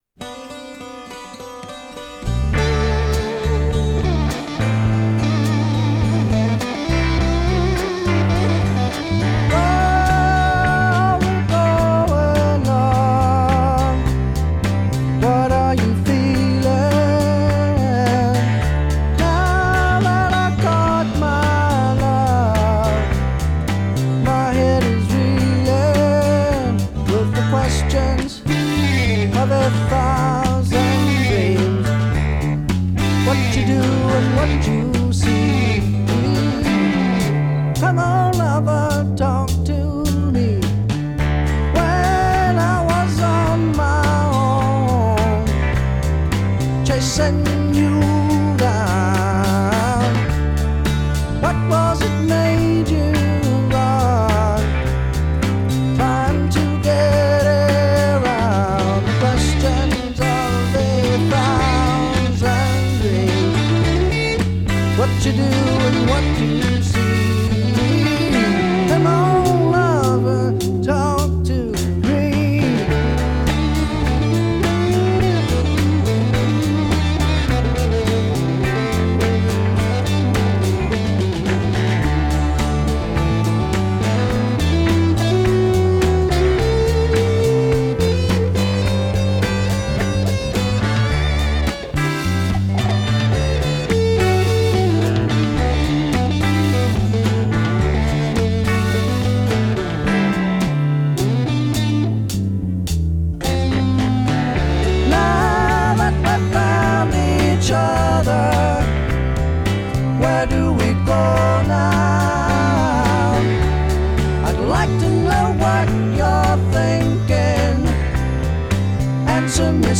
Country Rock
classic rock راک کانتری